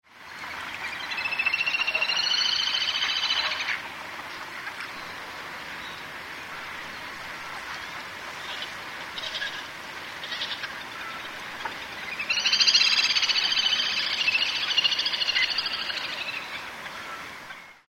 kaitsuburi_c1.mp3